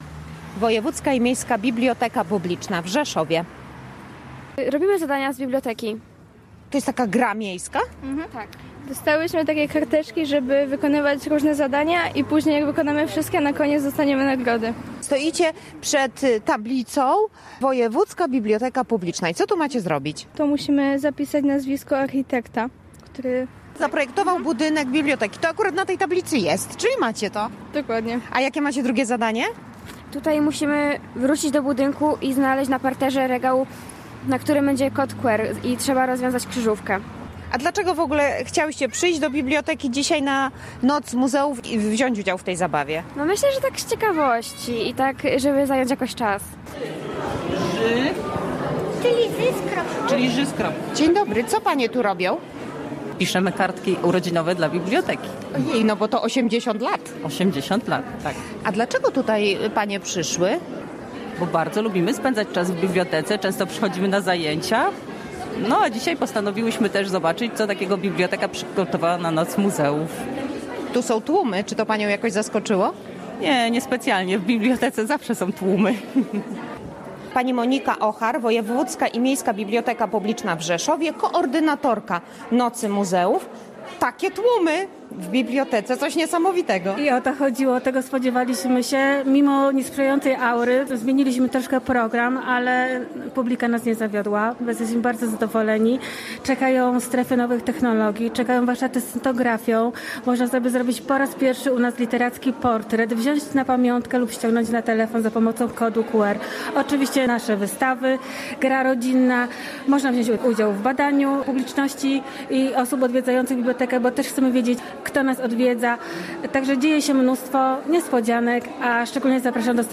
Tak było podczas Nocy Muzeów w Rzeszowie